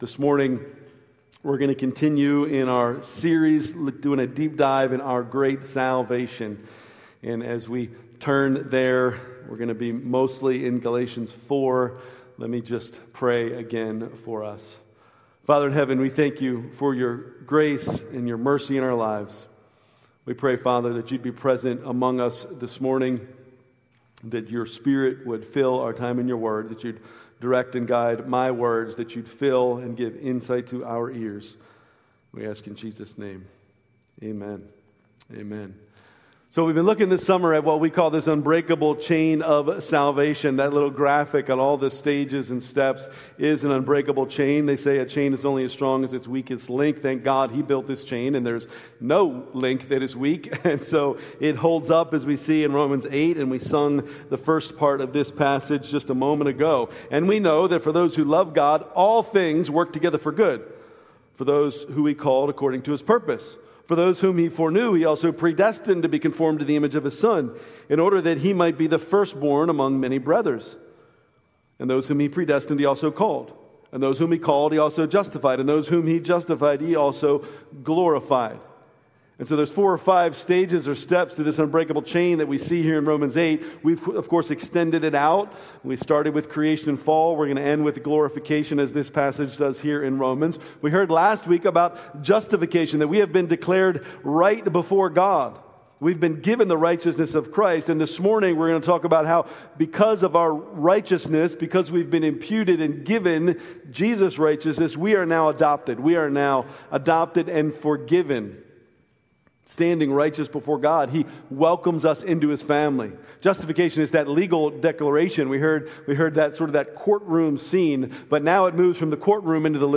July 27, 2025 Worship Service Order of Service: